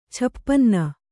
♪ chapanna